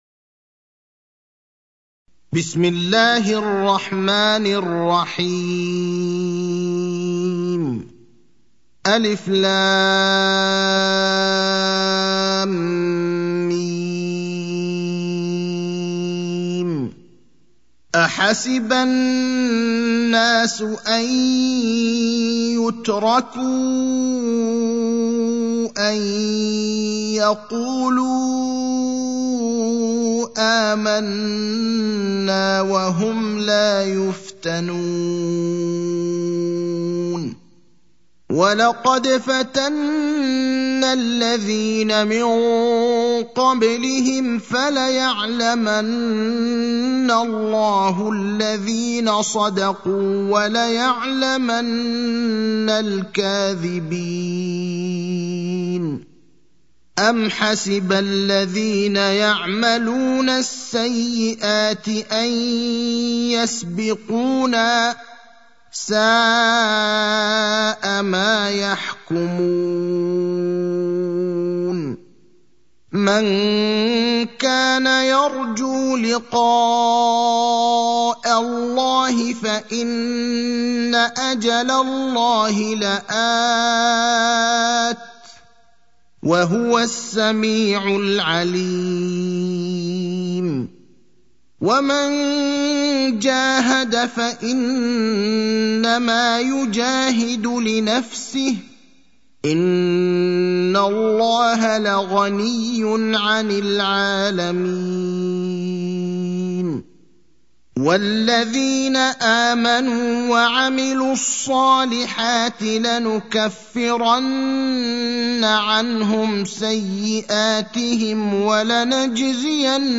المكان: المسجد النبوي الشيخ: فضيلة الشيخ إبراهيم الأخضر فضيلة الشيخ إبراهيم الأخضر العنكبوت (29)